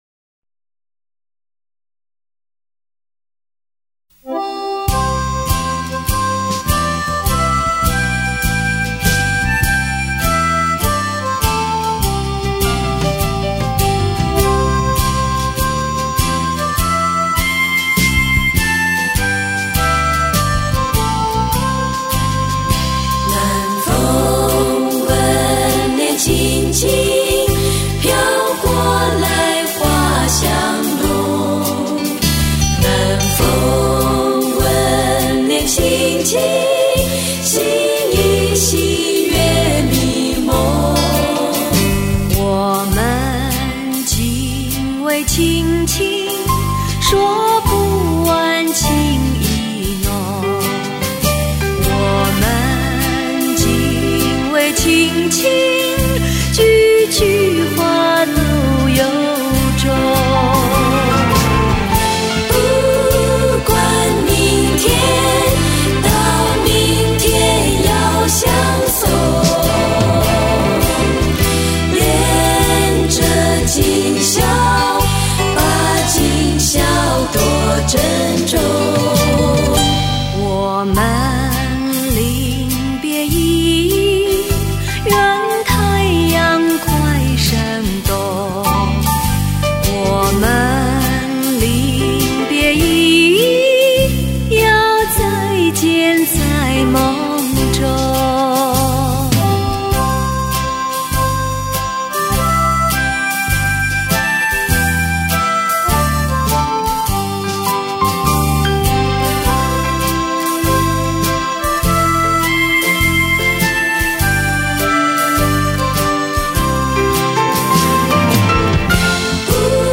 专辑中以女声小合唱形式演绎各个不同年代的经典老歌，别有一番韵味。